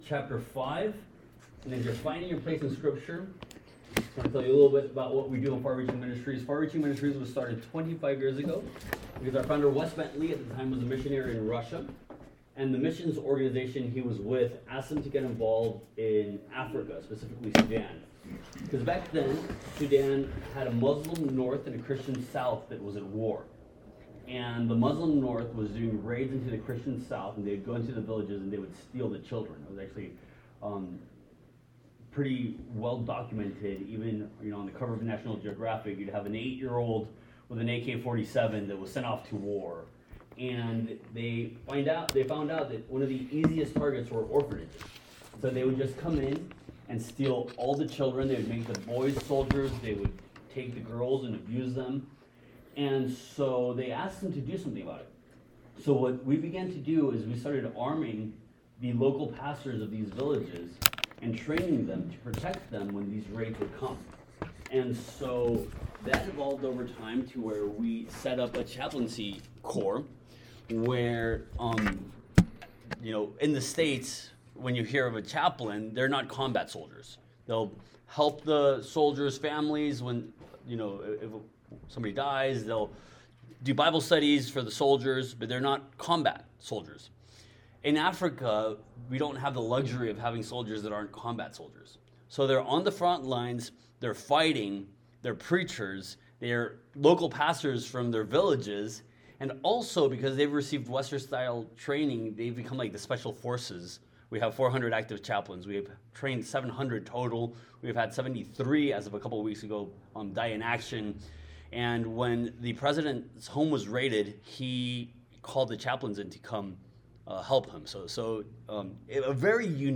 Series: Guest Speaker Service Type: Sunday Morning Topics: Missions